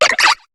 Cri de Moustillon dans Pokémon HOME.